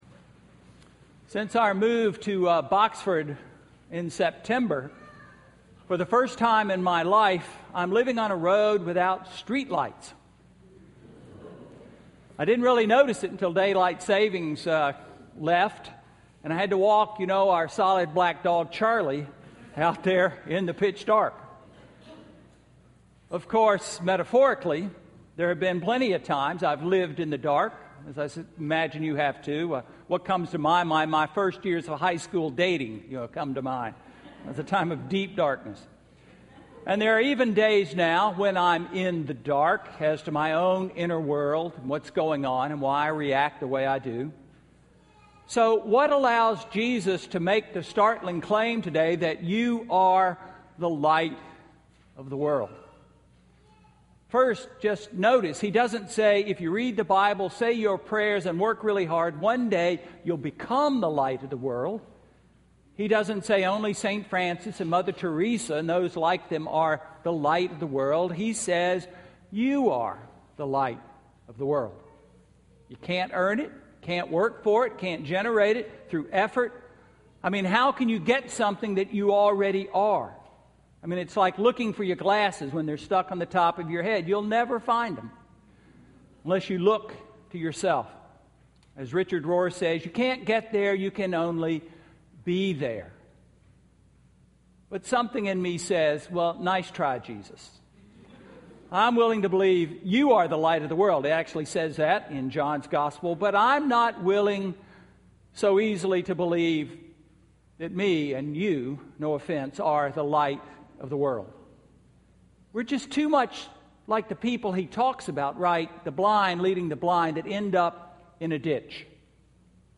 Sermon–February 9, 2014 – All Saints' Episcopal Church
Sermon–February 9, 2014